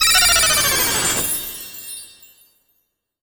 pgs/Assets/Audio/Magic_Spells/magic_device_transform_01.wav at master
magic_device_transform_01.wav